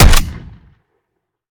Home gmod sound weapons xm109
weap_xmike109_sup_plr_01.ogg